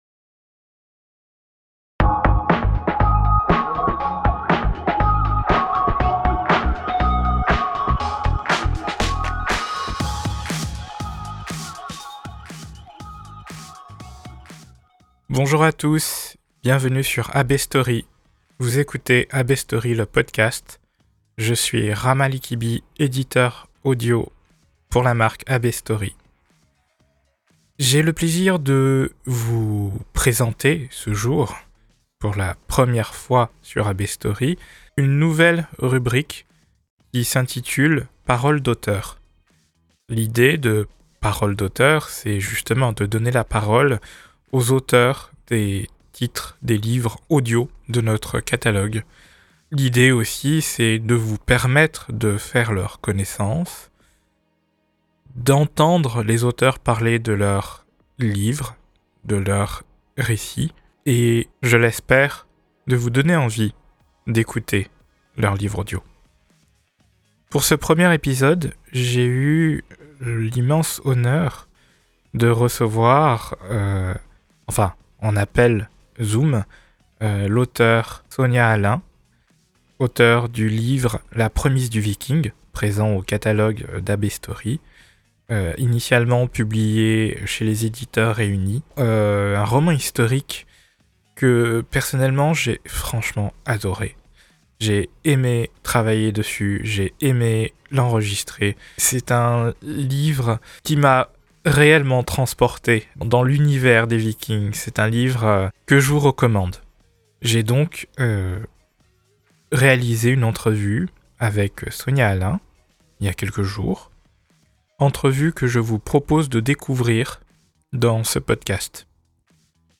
Entrevue
entrevue-la-promise-du-viking.mp3